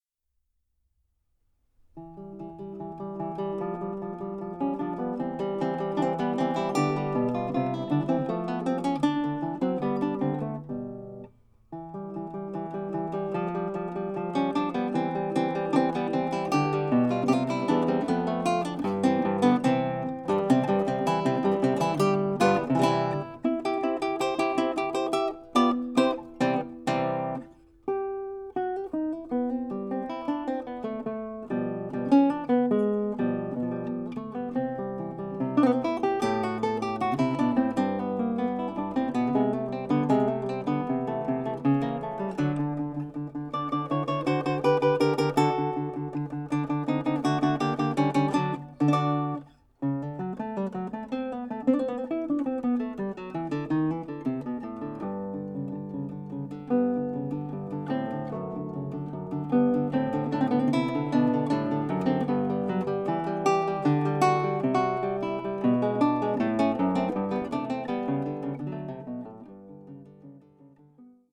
Sono qui presentate tre sonate per chitarra sola
chitarra